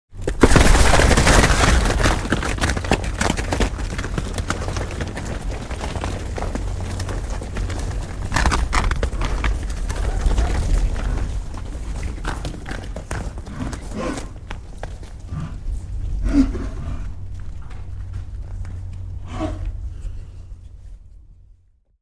Громкие, ритмичные удары копыт создают эффект присутствия – используйте для звукового оформления, релаксации или творческих проектов.
Звук стадного бегства (несколько животных пробегает мимо)